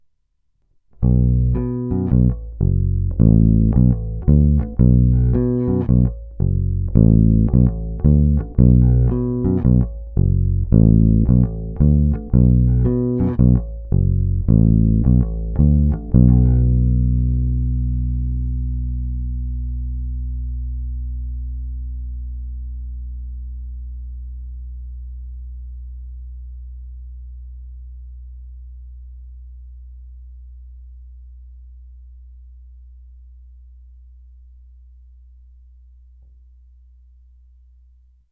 Struny mají výrazný vintage charakter a řádně ten agresívní zvuk umravnily. Opět ukázky provedeny přímo do zvukové karty a kromě normalizace ponechány bez jakýchkoli úprav.
Hráno mezi krkem a snímačem